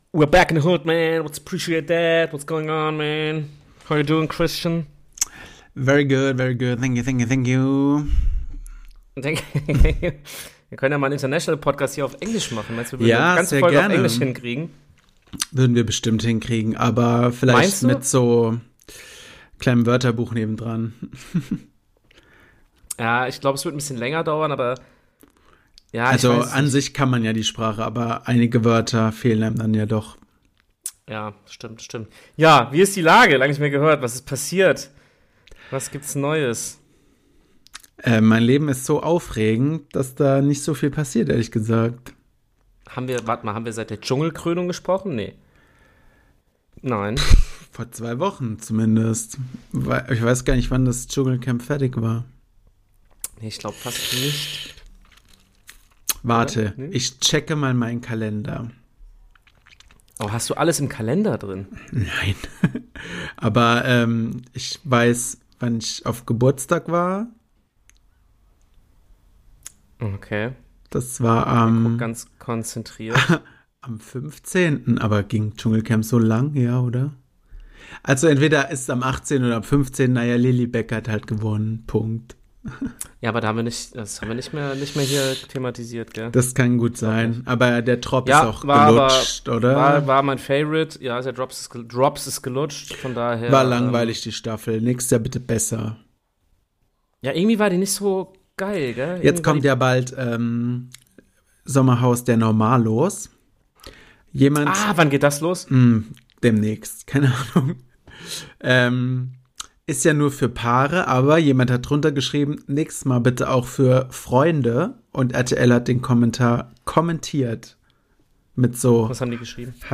Leberwurst gibt es auch noch drauf und eine traumhafte Gesangseinlage.